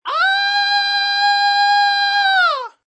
Descarga de Sonidos mp3 Gratis: grito 21.